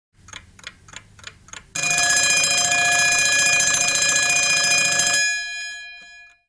Wecktöne